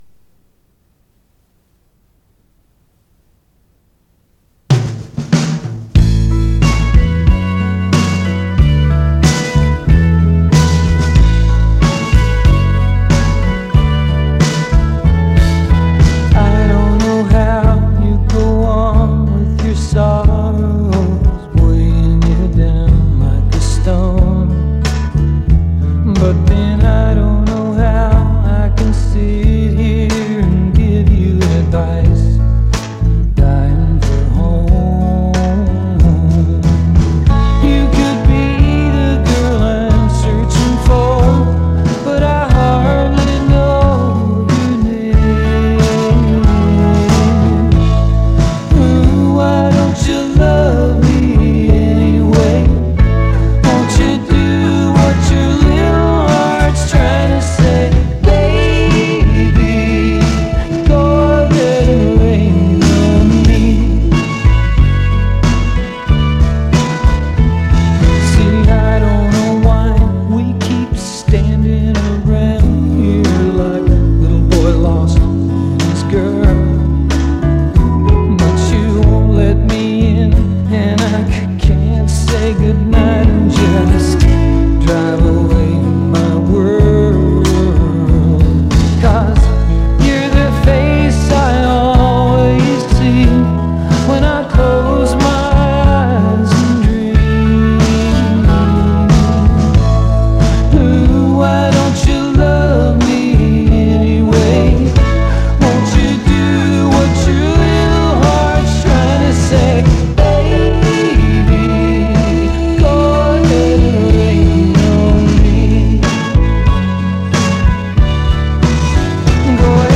磁带数字化：2022-09-19